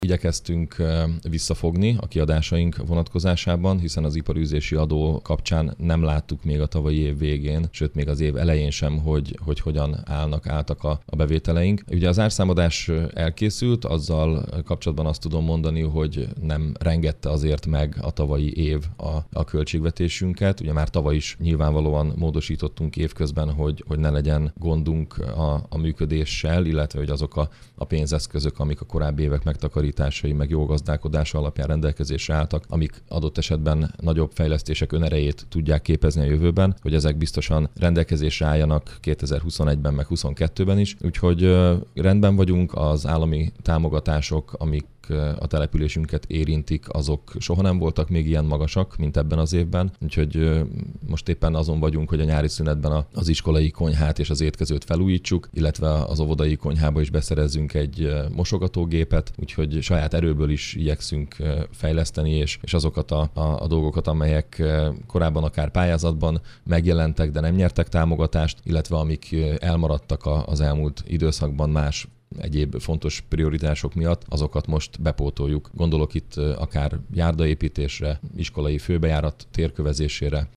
Ezen kívül az óvodai konyha is új mosogatógépet kap. A település költségvetése stabil, a tavalyi módosításokkal biztosítani tudják az idei év fejlesztéseit. Mészáros Sándor polgármestert hallják.